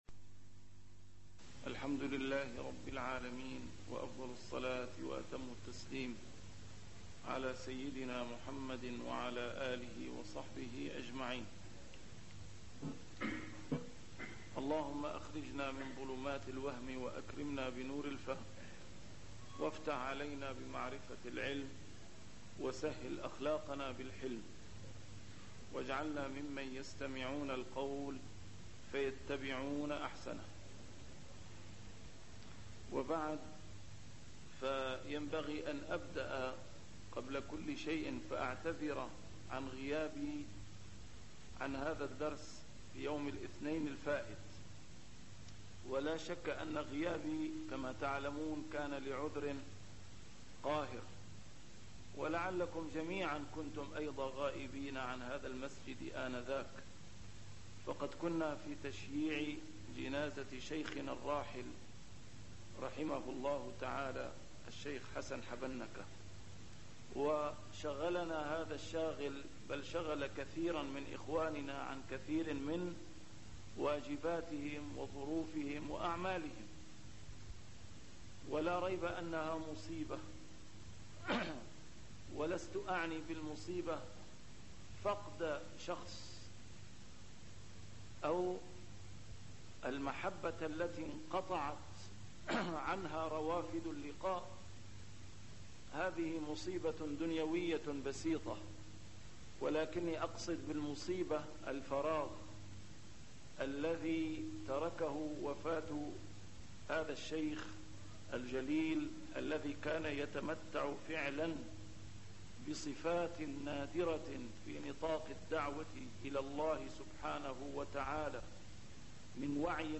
نسيم الشام › A MARTYR SCHOLAR: IMAM MUHAMMAD SAEED RAMADAN AL-BOUTI - الدروس العلمية - شرح الأحاديث الأربعين النووية - تتمة شرح الحديث الثالث والعشرون: حديث مالك بن الحارث (الطهور شطر الإيمان) 76